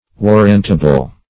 Warrantable \War"rant*a*ble\, a.